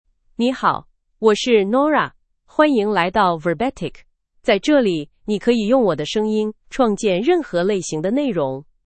Nora — Female Chinese (Mandarin, Simplified) AI Voice | TTS, Voice Cloning & Video | Verbatik AI
Nora is a female AI voice for Chinese (Mandarin, Simplified).
Voice sample
Listen to Nora's female Chinese voice.
Female